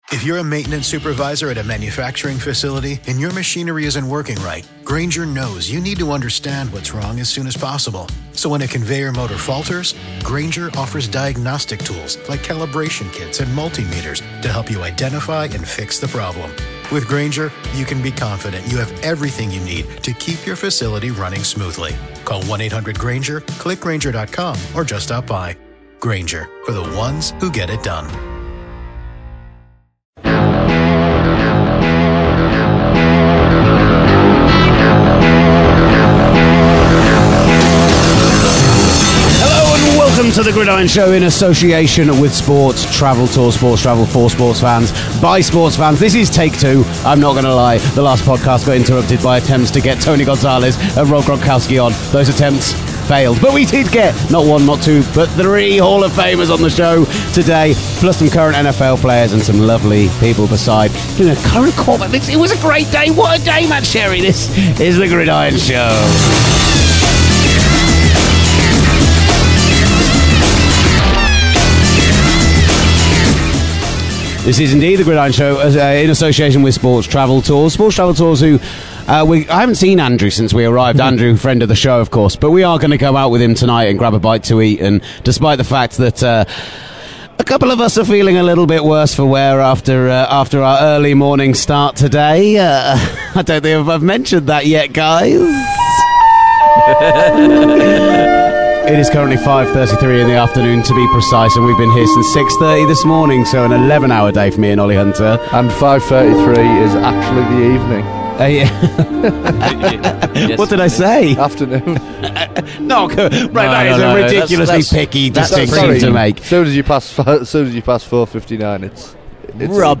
Another cracking day on radio row including no less than 3 hall for of farmers!